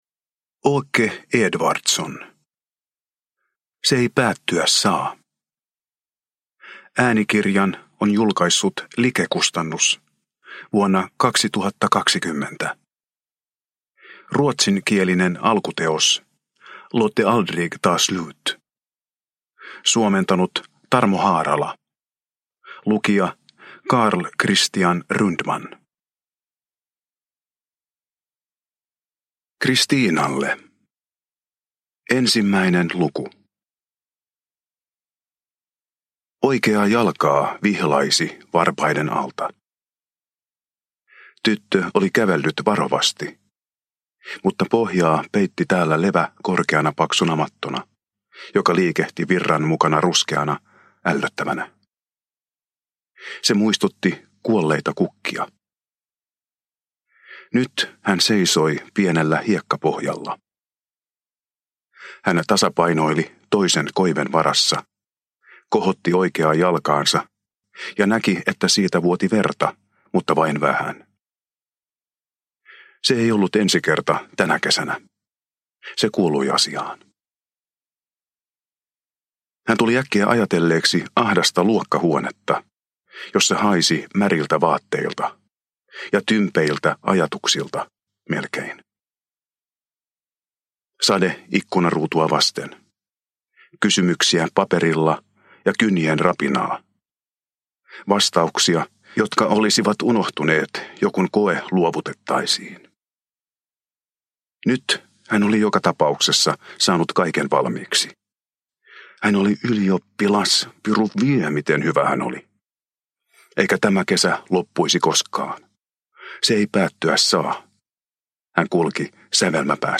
Se ei päättyä saa – Ljudbok – Laddas ner